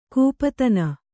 ♪ kūpatana